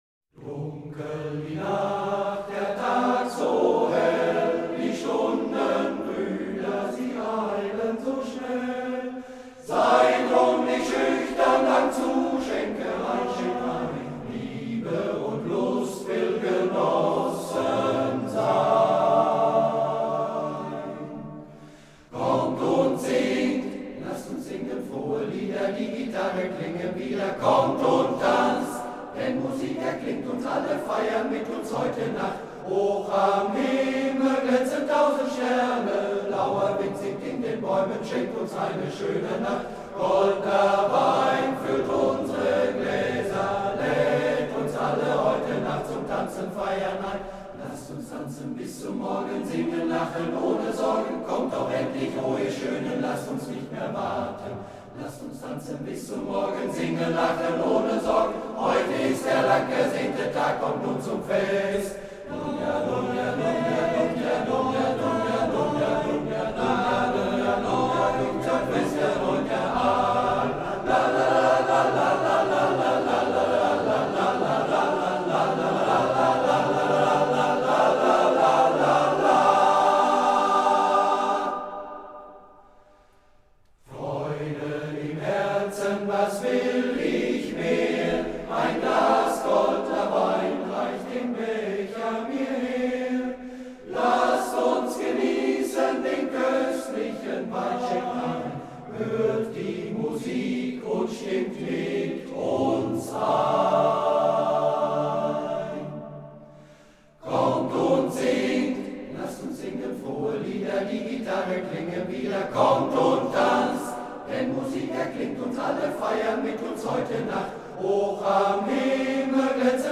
Zur Karte der Stelenstandorte "Musik erklingt" gesungen vom MGV "Cäcila" Volkringhausen e.V. Um das Lied zu hören, bitte demnächst auf unser Bild klicken.